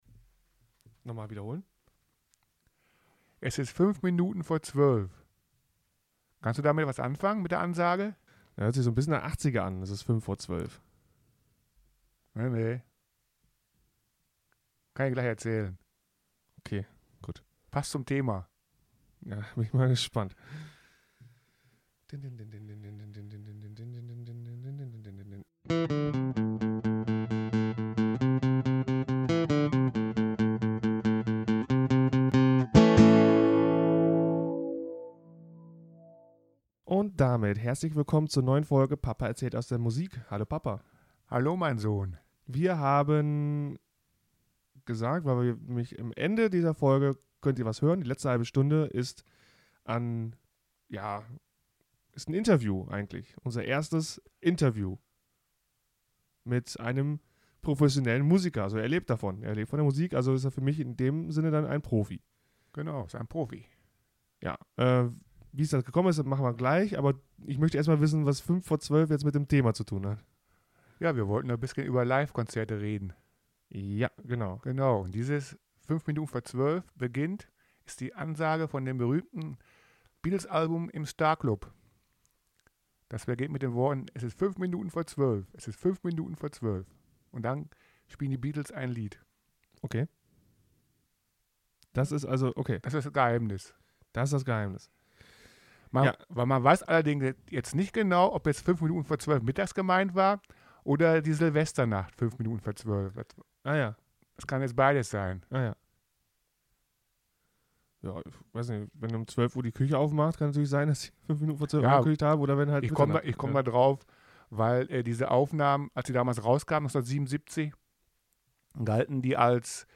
Über kleine Auftritte und Konzerte - Ein Interview